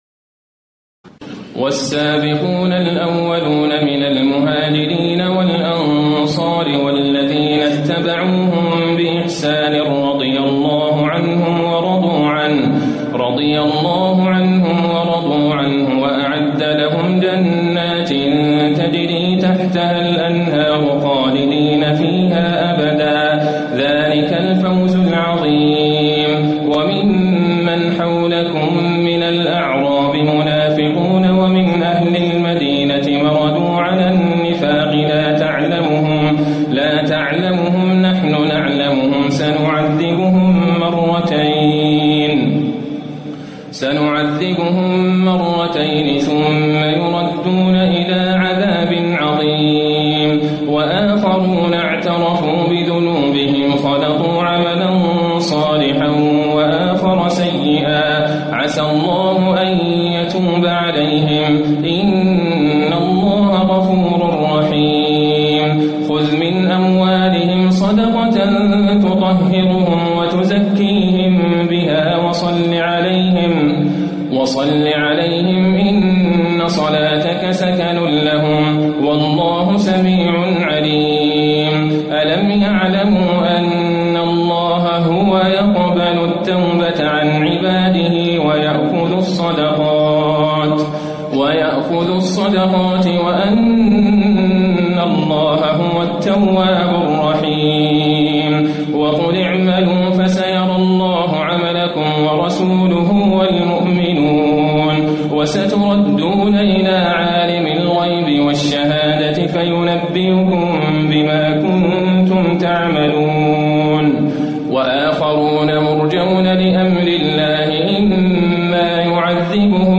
تراويح الليلة العاشرة رمضان 1437هـ من سورتي التوبة (100-129) و يونس (1-53) Taraweeh 10 st night Ramadan 1437H from Surah At-Tawba and Yunus > تراويح الحرم النبوي عام 1437 🕌 > التراويح - تلاوات الحرمين